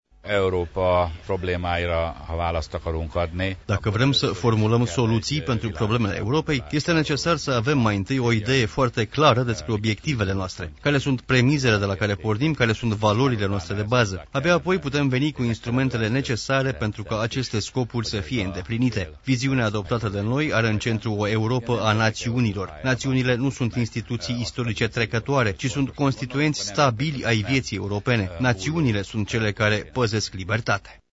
Nemeth Zsolt a declarat pentru Radio România că tot mai des soarta Europei devine o problemă a naţiunilor: